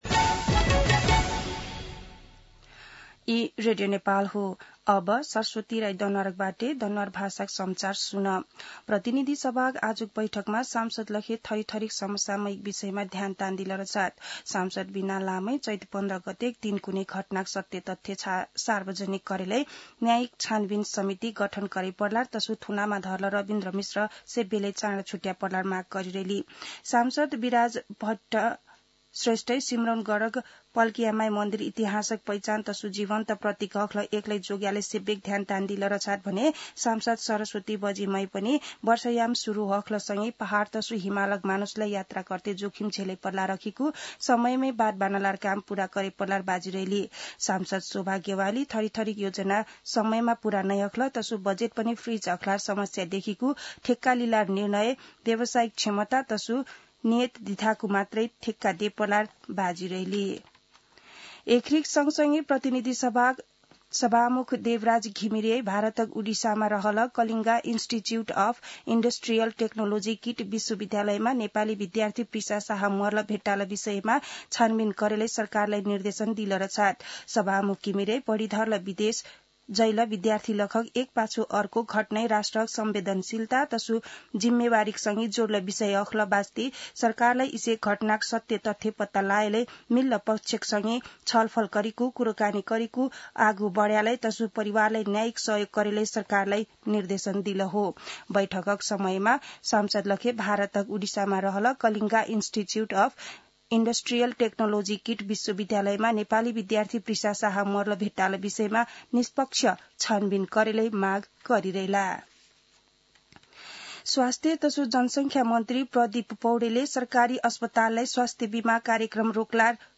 दनुवार भाषामा समाचार : २२ वैशाख , २०८२
Danuwar-News-1-22.mp3